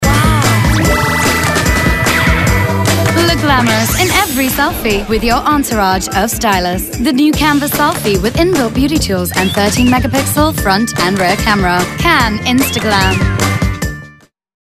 Young urban voice for fashion